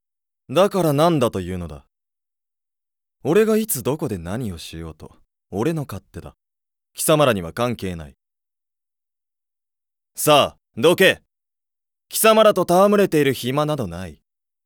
ボイスサンプル
クール系青年